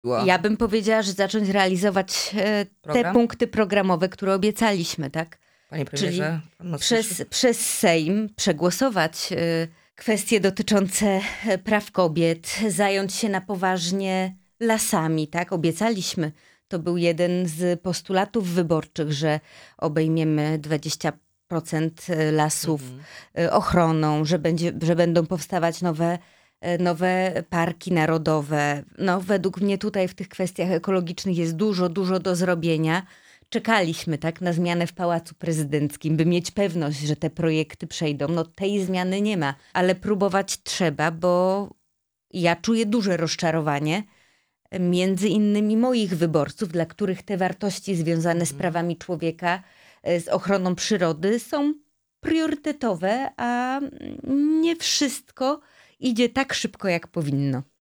Posłanka na Sejm Małgorzata Tracz byłą naszym „Porannym Gościem”.